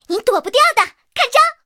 SU-26开火语音1.OGG